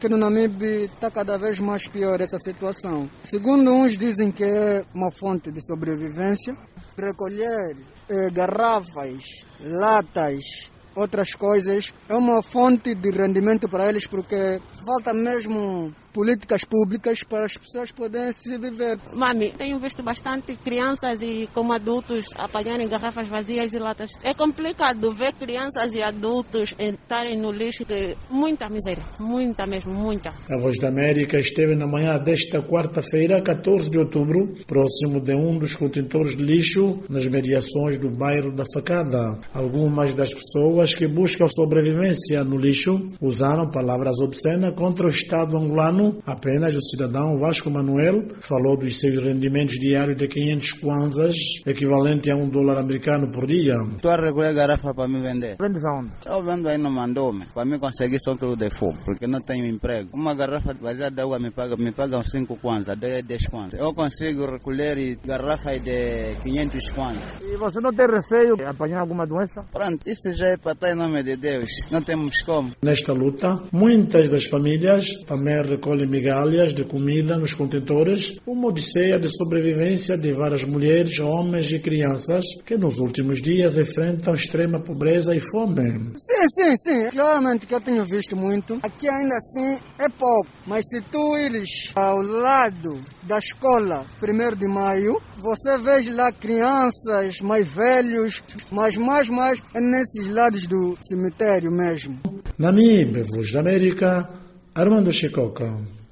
A voz de América esteve na manhã desta quarta-feira, 14, próximo de um dos contentores de lixo nas mediações do bairro da Faca.